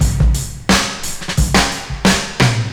I’ve been having a lot of fun with IK Multimedia MODO Drum 2. Everything is pretty tweakable and sounds pretty natural.
I tried to recreate some famous breaks with varying degrees of success.